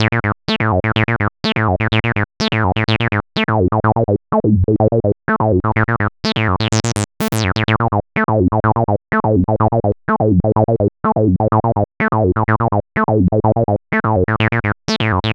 cch_acid_loop_juno_125.wav